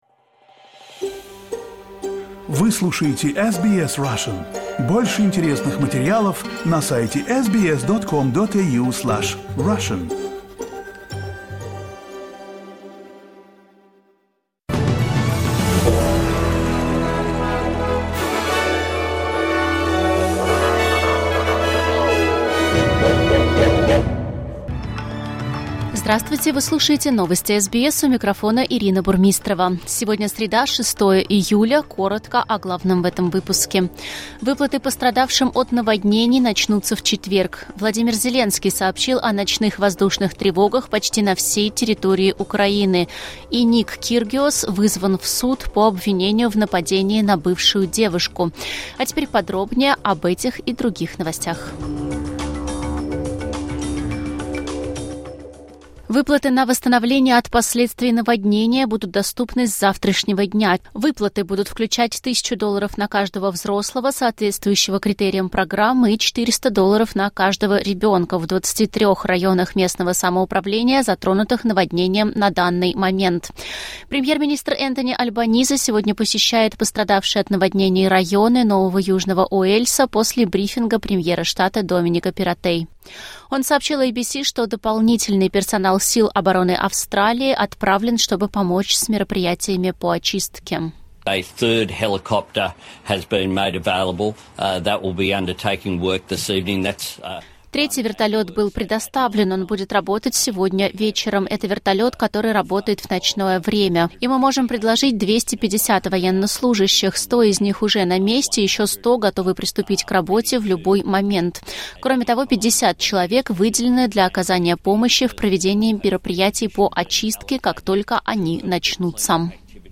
SBS news in Russian - 6.07.2022